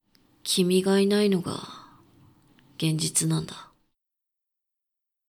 ボイス
低音男性
dansei_kimigainainogagenzitunanda.mp3